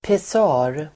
Ladda ner uttalet
Uttal: [pes'a:r]